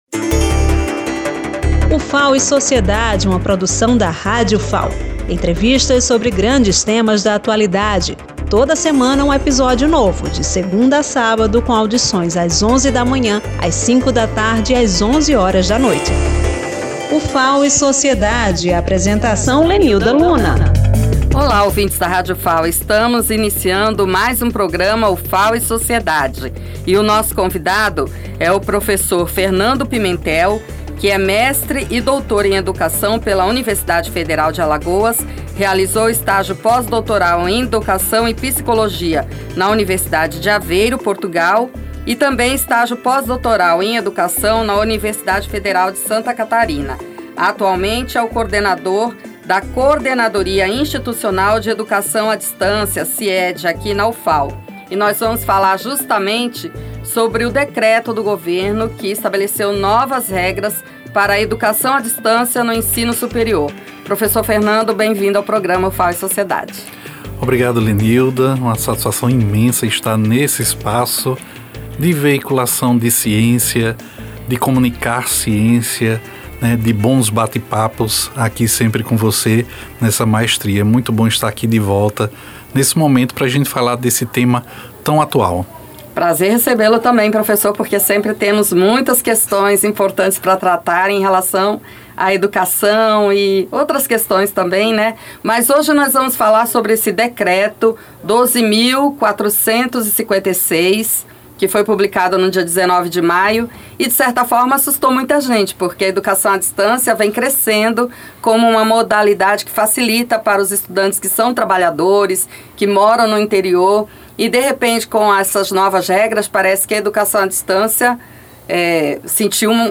Durante a entrevista